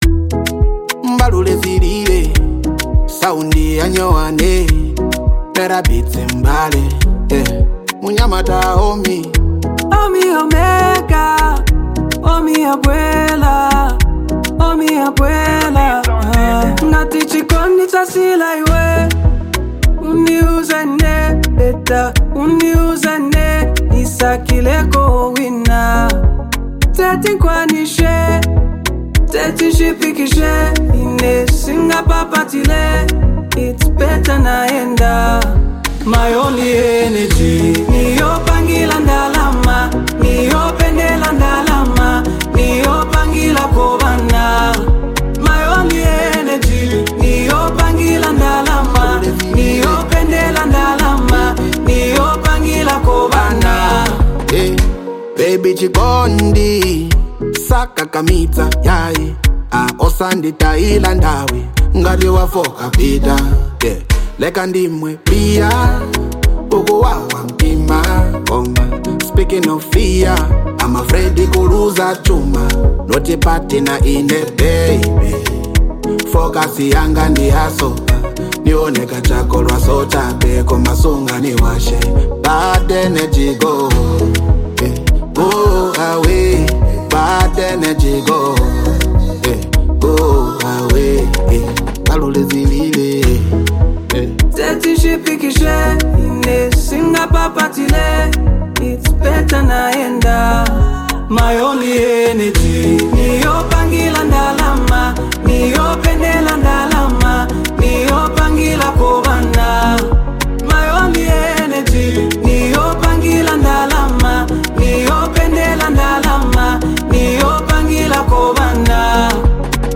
upbeat delivery and catchy melodies
smooth, charismatic vocals